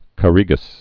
(kə-rēgəs, -rā-)